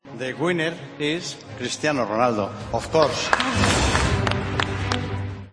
Redacción digital Madrid - Publicado el 27 dic 2016, 21:56 - Actualizado 17 mar 2023, 19:44 1 min lectura Descargar Facebook Twitter Whatsapp Telegram Enviar por email Copiar enlace Así desveló el presidente del Real Madrid el nombre del ganador como mejor jugador del mundo en la séptima edición de los Globe Soccer Awards.